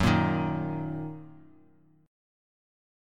F#6 Chord
Listen to F#6 strummed